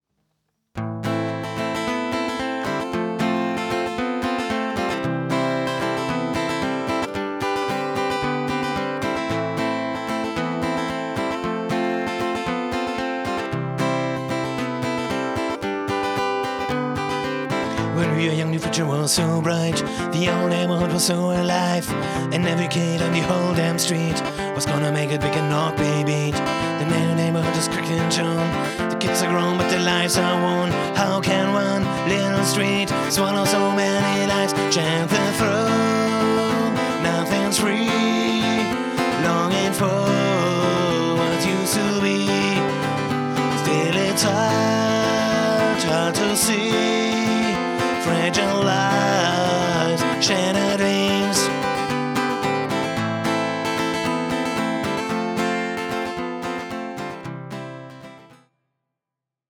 Rock & Pop Cover